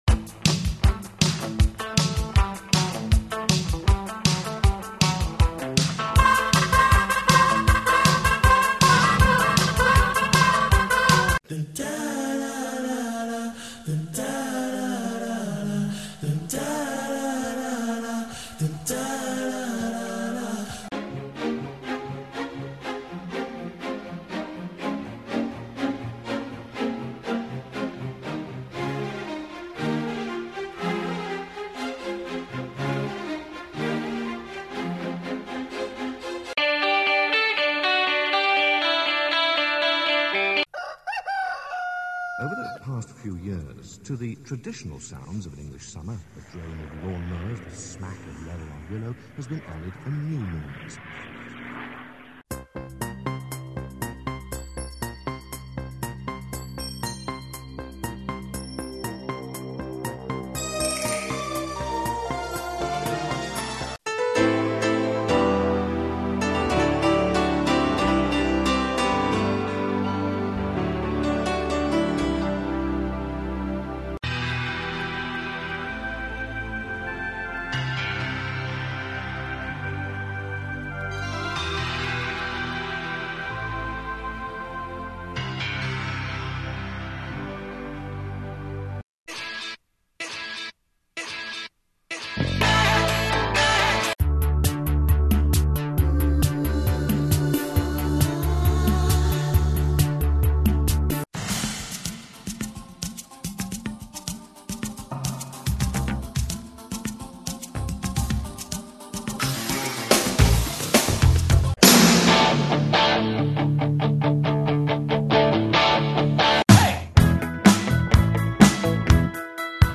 The Great Big Song Intro Quiz I made this! How well do you know your song intros? think you can name 300 of them? Most of them are from the 80s & 90s, back when songs were songs. The intros average 10 seconds, some being as small as under 2 seconds because they're so instantly recognisable and some longer to give you a chance.